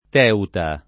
Teuta [ t $ uta ]